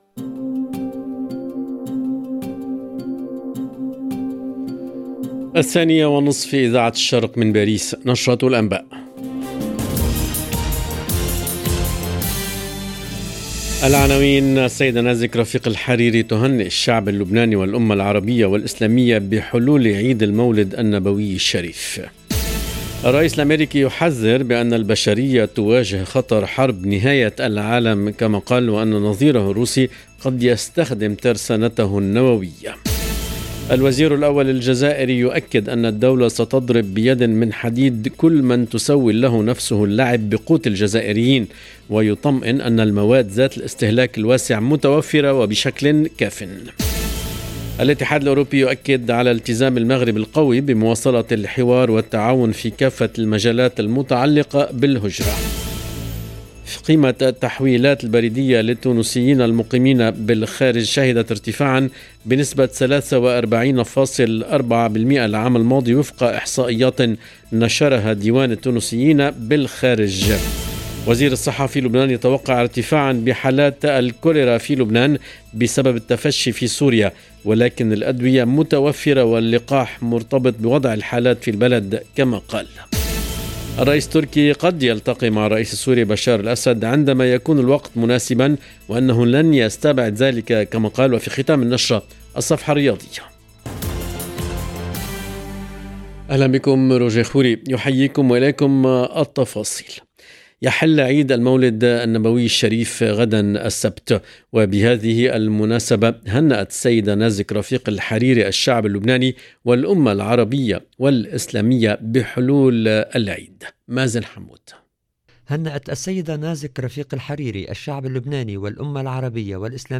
LE JOURNAL EN LANGUE ARABE DE LA MI-JOURNEE DU 7/10/22